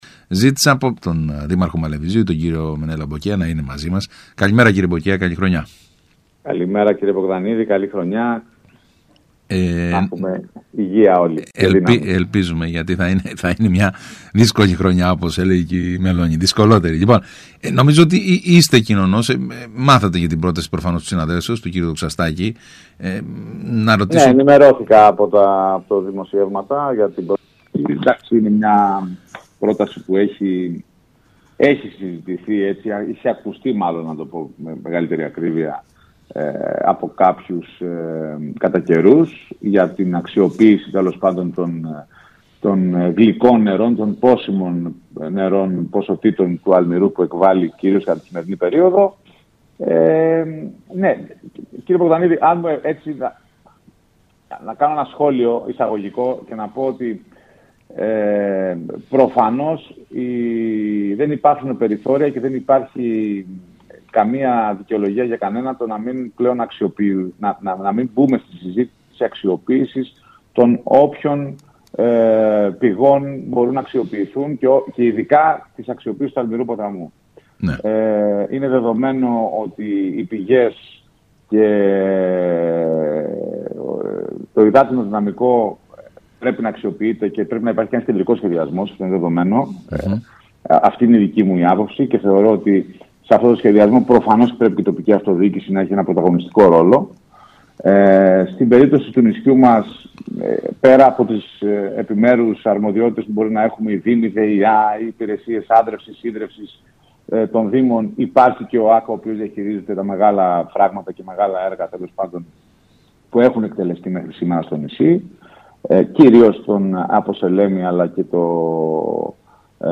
ΣΚΑΪ Κρήτης 92,1